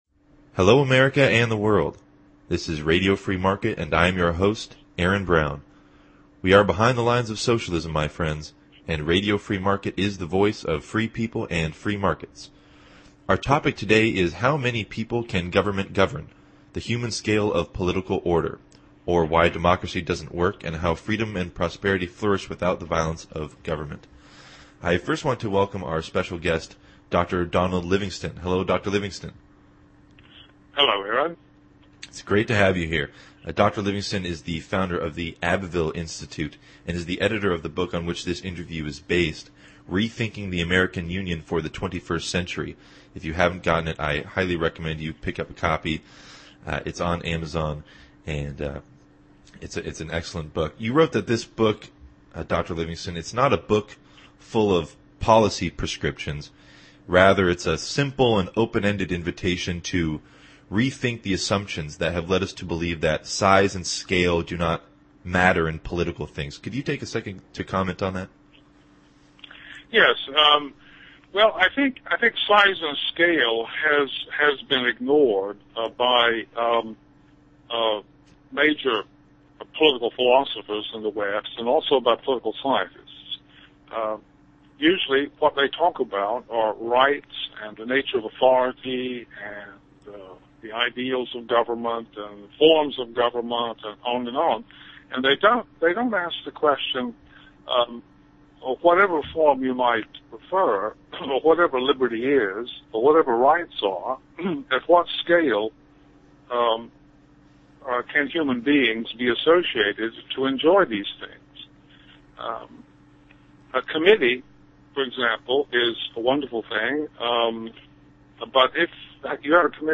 We discuss these issues and more in this interview.